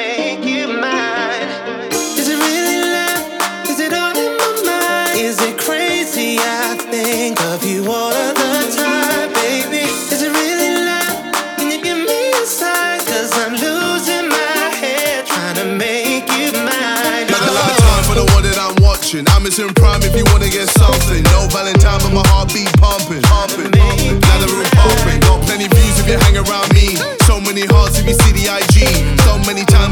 • Pop
infectious chorus